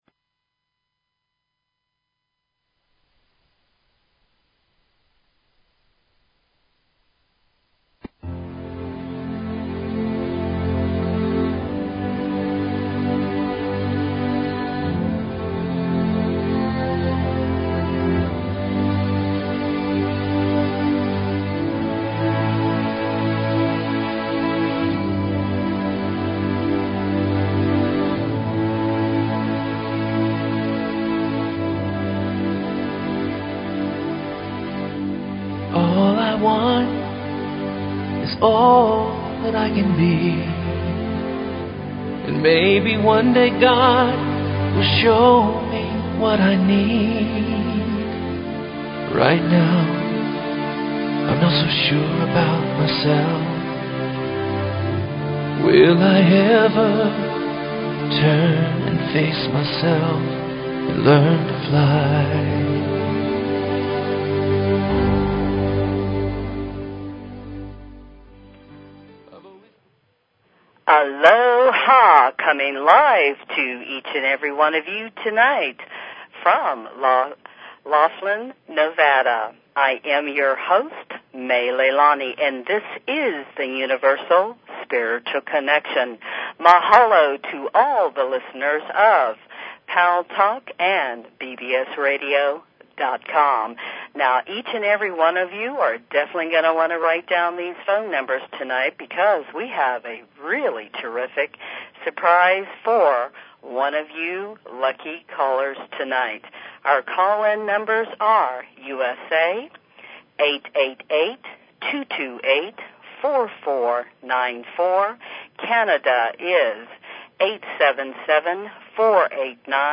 Talk Show Episode, Audio Podcast, Universal_Spiritual_Connection and Courtesy of BBS Radio on , show guests , about , categorized as
This show is Fun and opens up a whole new world of exciting topics and "Welcomes" your Questions and Comments. The show explores a variety of subjects from the Spiritual Eastern, Western and Native American Indian beliefs to the basic concepts of Love and Success.